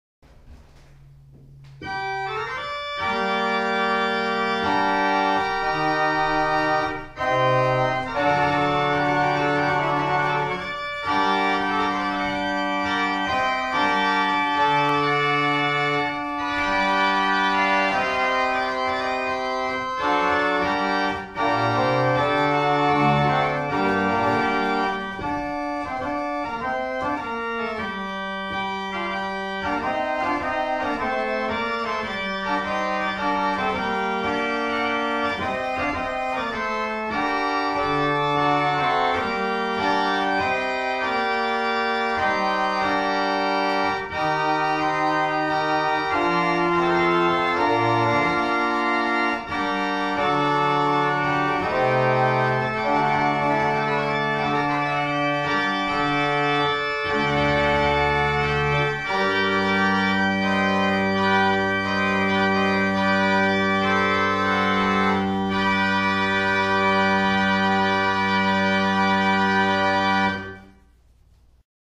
1802 Tannenberg Organ
Hebron Lutheran Church - Madison, VA
The Mixtur is 2 ranks and has a single break at middle c. The Terzan is an unusual stop that was possibly meant to give a similar effect as adding a Trumpet. The rank begins at 1 3/5' and breaks to 3 1/5 at middle c. The effect of this stop is really quite splendid.
an improvisation on the plenum with the Terzan